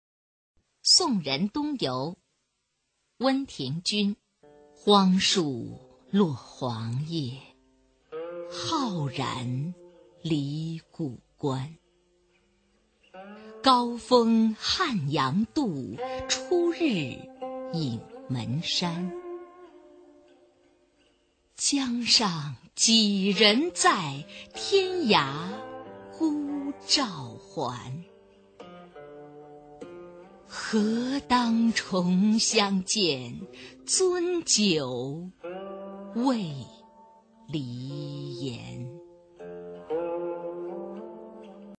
[隋唐诗词诵读]温庭筠-送人东游 配乐诗朗诵